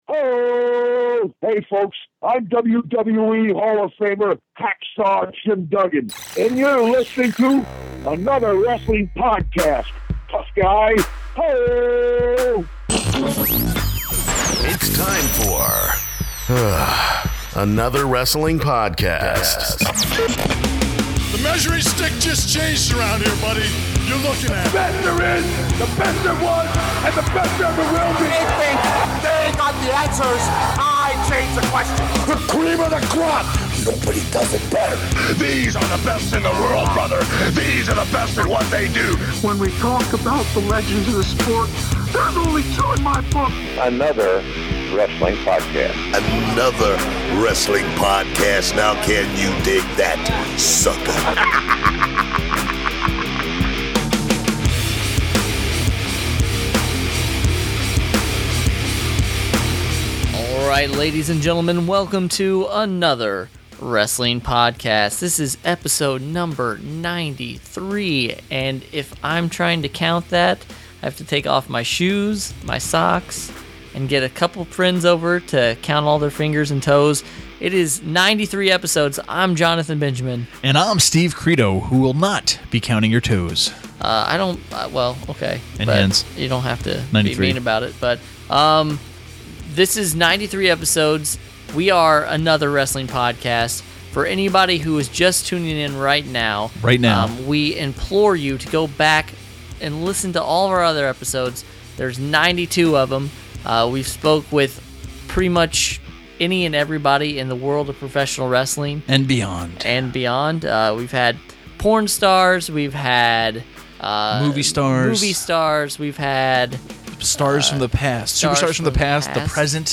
Stopping by off his 2x4 comedy tour is WWE Hall of Famer Hacksaw Jim Duggan. He talks to us about his comedy show, the Royal Rumble, Andre the Giant, Super Bowl predictions & a whole lot more!